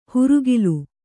♪ hurugilu